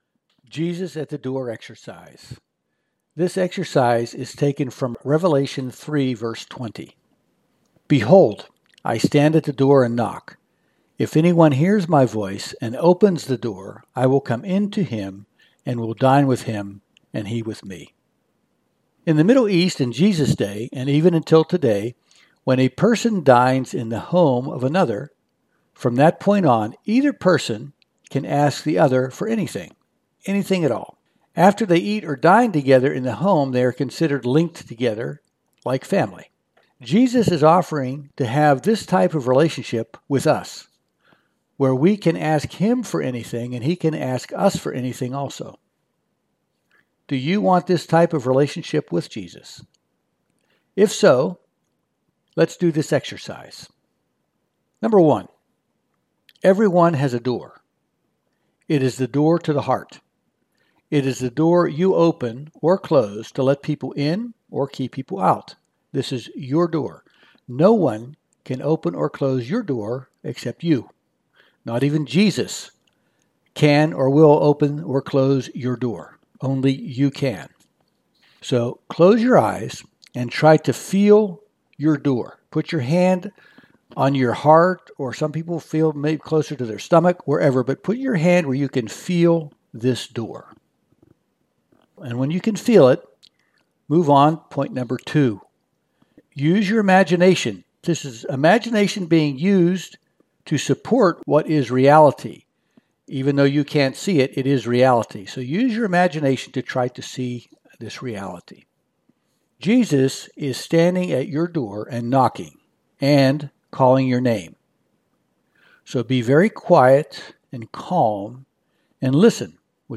Jesus at Door Exercise (6 minutes)